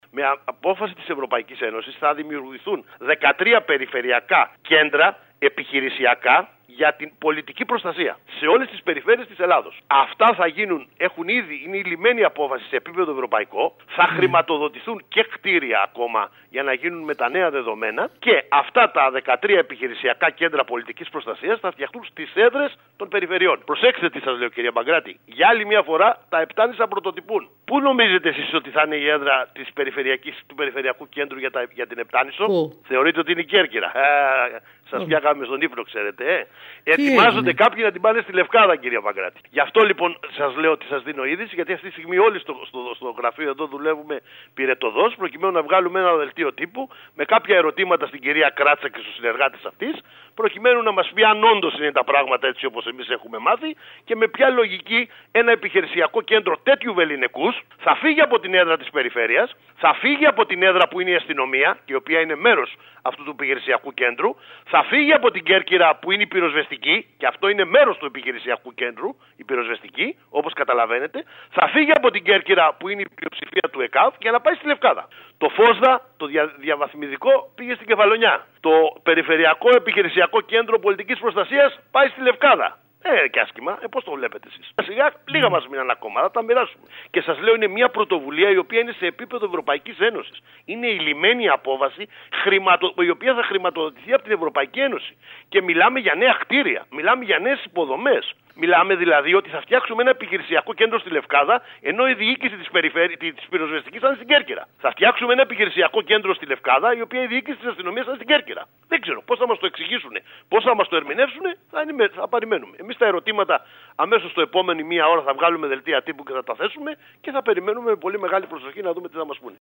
Η έδρα όμως για την περιφέρεια Ιονίων Νήσων θα γίνει στη Λευκάδα. Αυτή την ενημέρωση είχε ο βουλευτής του ΚΙΝΑΛ Δημήτρης Μπιάγκης, ο οποίος σε δηλώσεις του σήμερα στην ΕΡΑ ΚΕΡΚΥΡΑΣ δημοσιεύει το θέμα μαζί με τα ερωτήματα του.